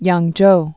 (yängjō)